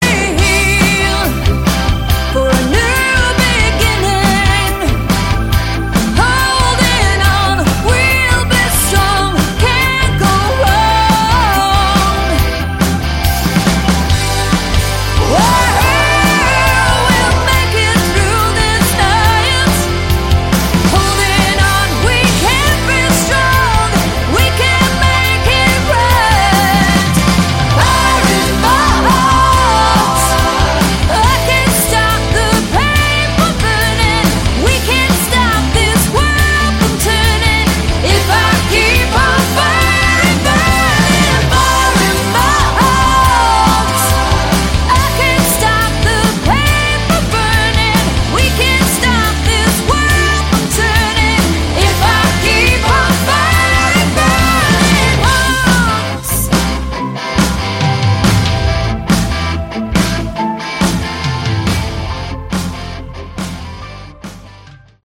Category: Hard Rock
lead vocals
lead guitar, backing vocals
bass Guitar
keyboards